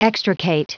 Prononciation du mot extricate en anglais (fichier audio)
Prononciation du mot : extricate